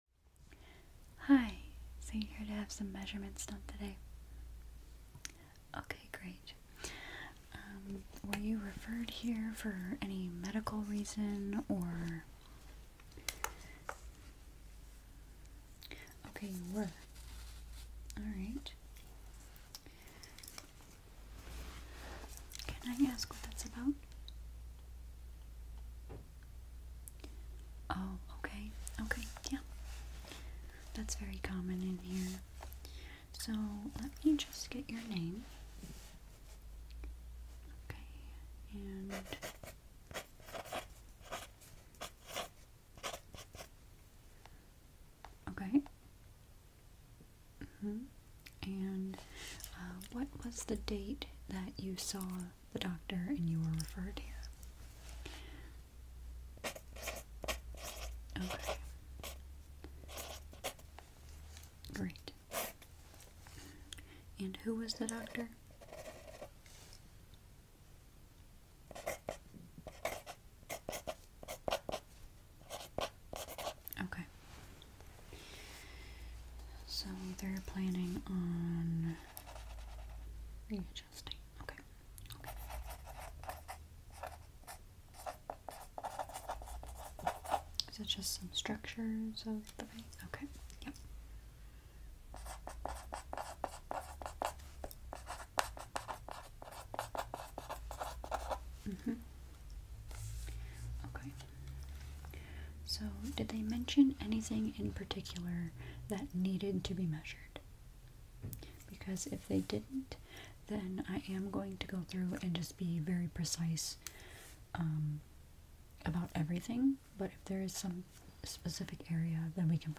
wonderful creator always fall asleep to her tingly asmr.